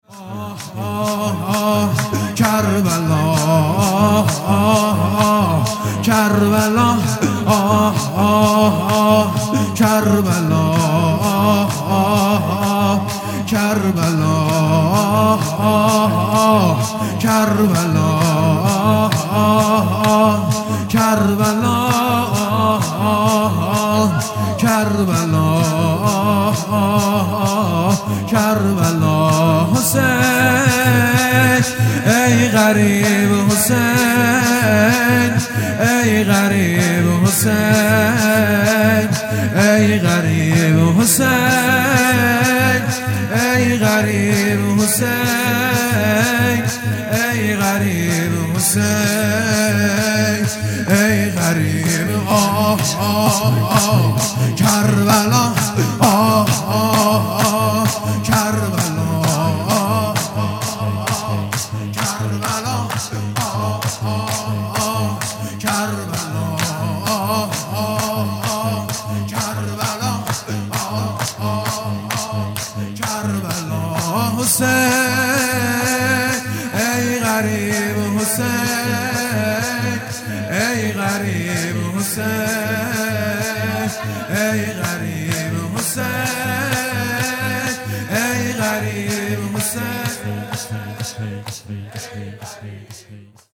نوا
جلسه هفتگی